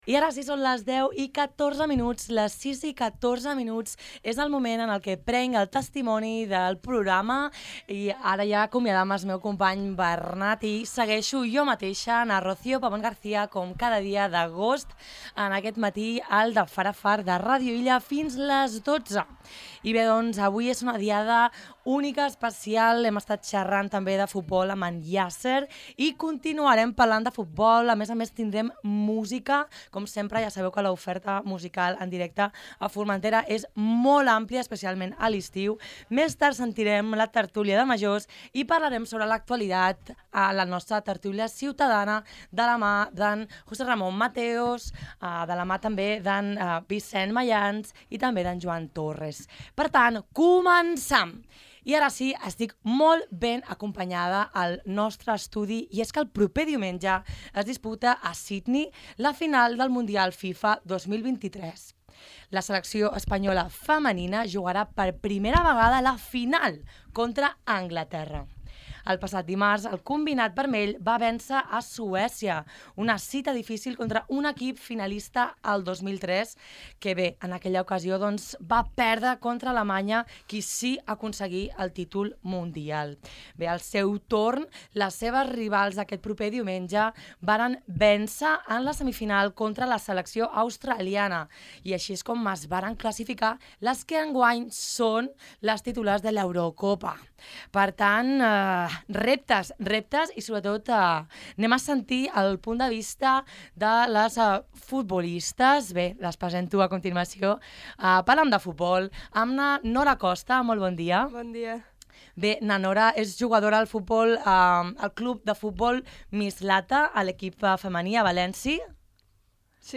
Avui al De Far a Far convocam una taula rodona per compartir la passió pel futbol, en la setmana de la final del mundial FIFA 2023, celebrat a Austràlia i Nova Zelanda.